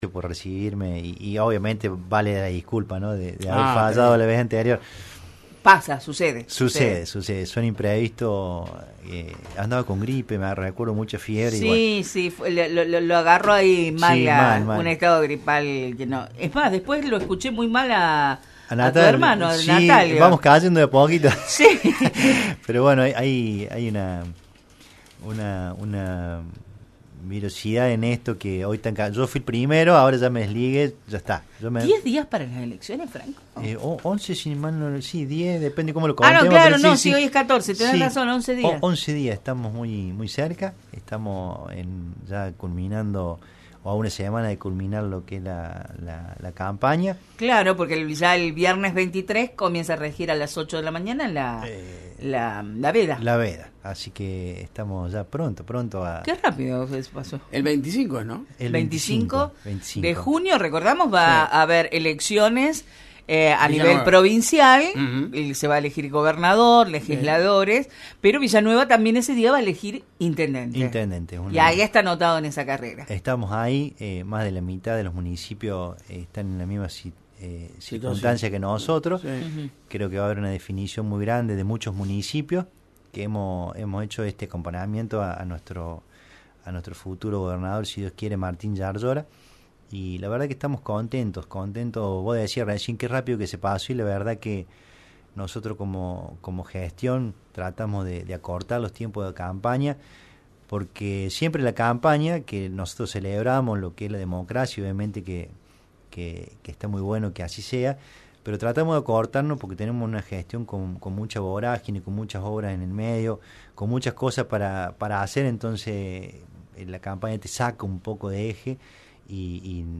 pasó por el estudio de Radio Centro, y en «La Mañana Informal» plasmó muchas de las ideas que tiene para la ciudad de Villa Nueva.